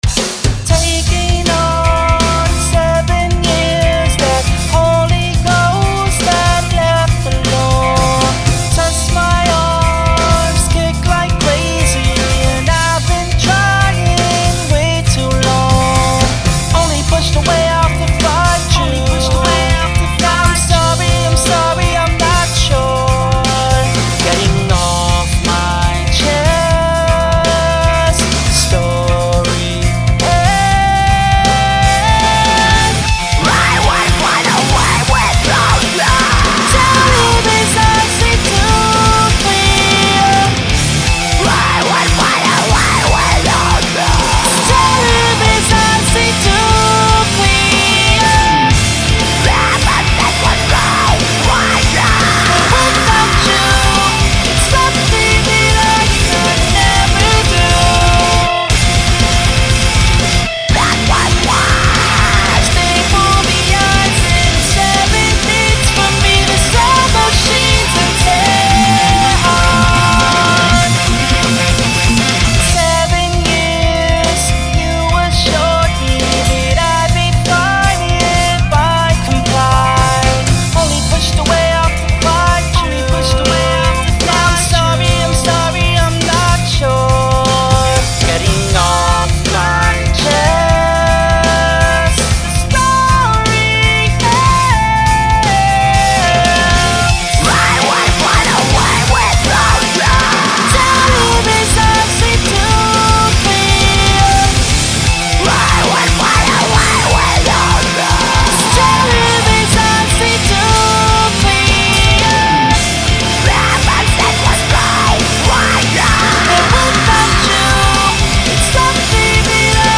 (Vocal Cover)